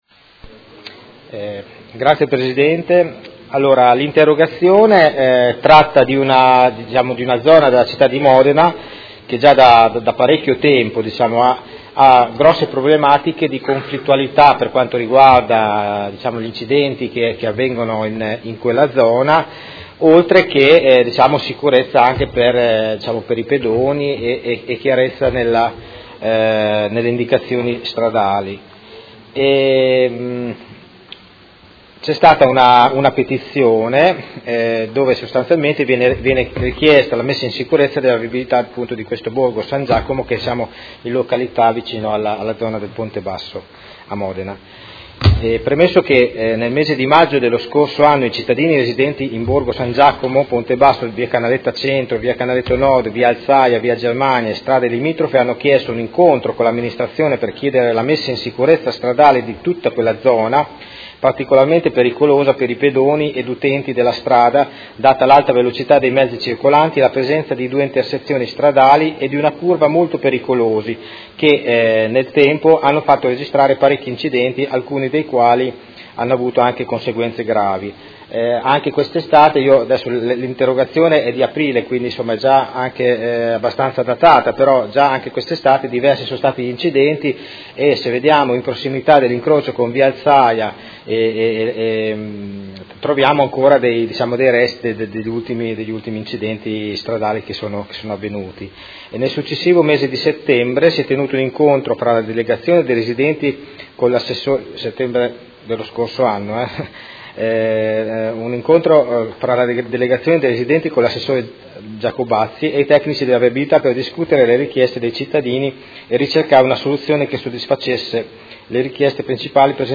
Vincenzo Walter Stella — Sito Audio Consiglio Comunale
Interrogazione del Consigliere Stella (Art1-MDP/Per Me Modena) avente per oggetto: Petizione con richiesta di messa in sicurezza della viabilità di Borgo San Giacomo in località Ponte basso a Modena